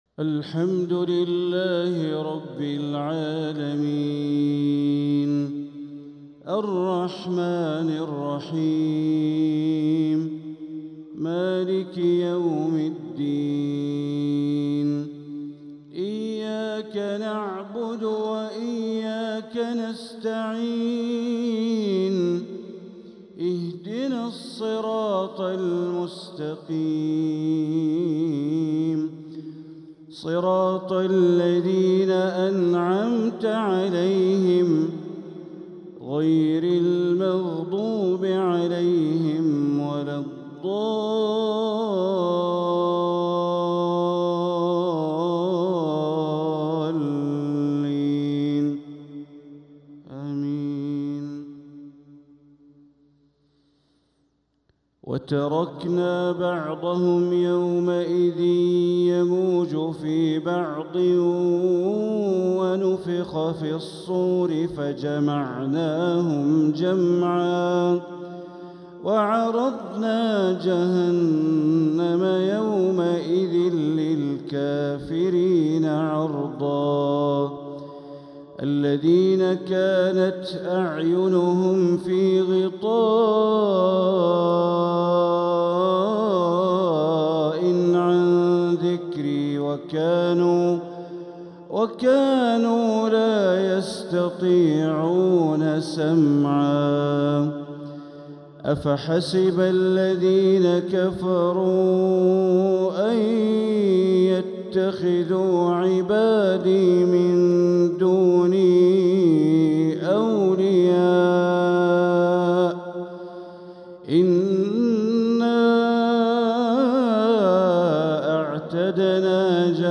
تلاوة من سورة الكهف 99-110 | مغرب الجمعة 2-1-1447هـ > 1447هـ > الفروض - تلاوات بندر بليلة